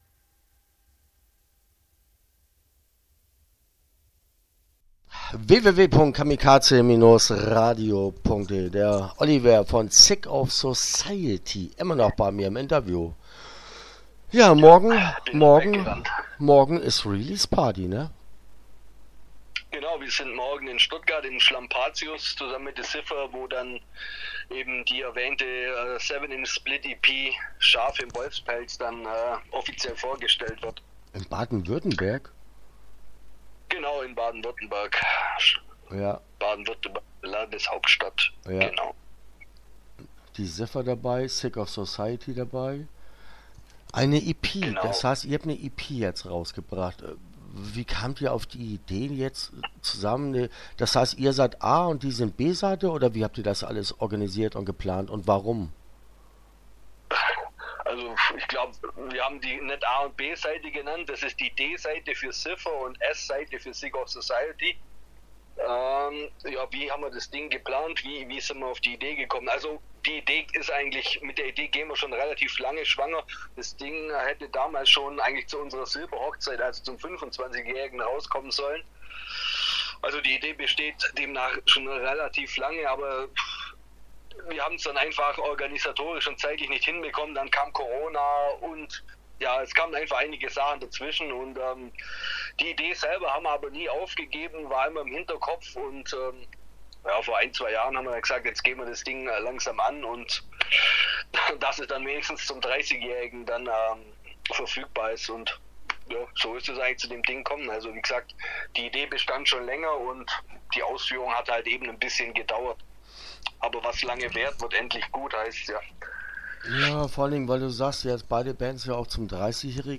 Sick Of Society - Interview Teil 1 (11:33)